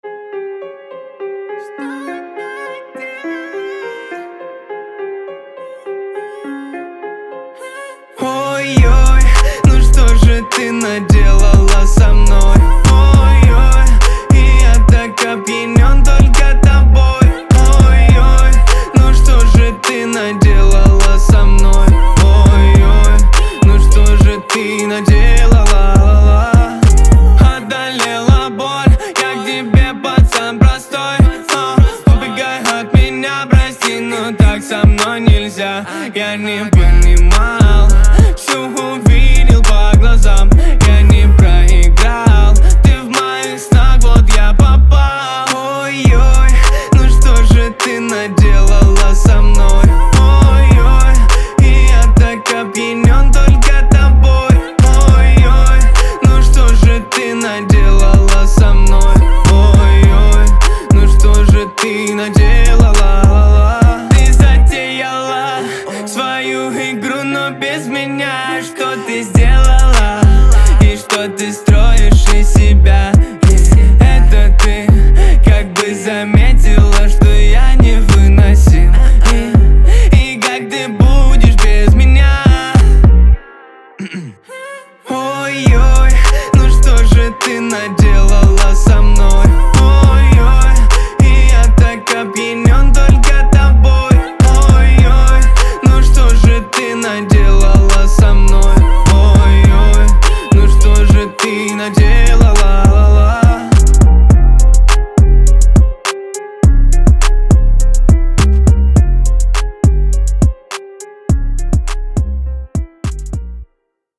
выразительному вокалу и искреннему исполнению